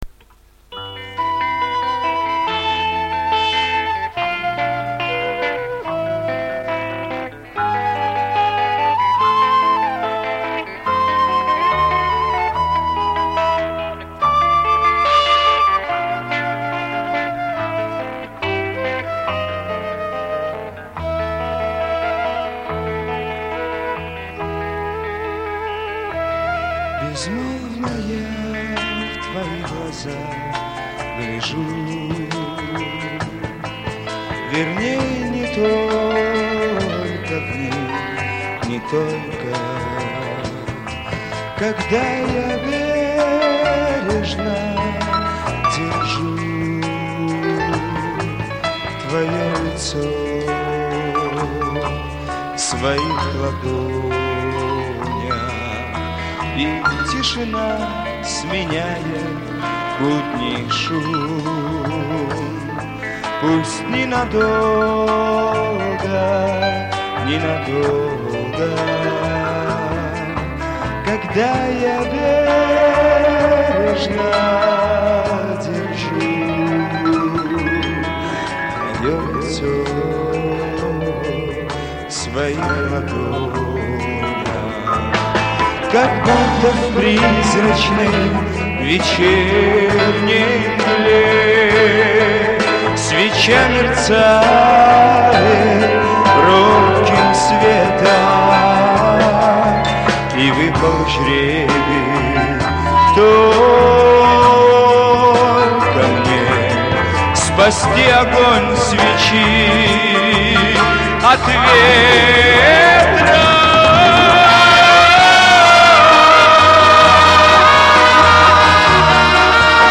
(концертный вариант)